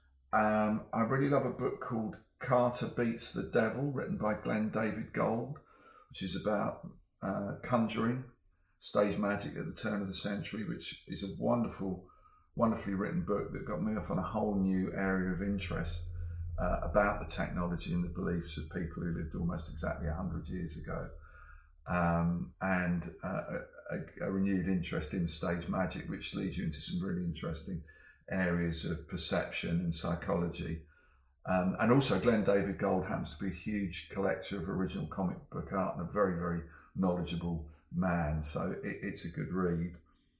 Dave Gibbons interview: Do you have any movies or book recommendations?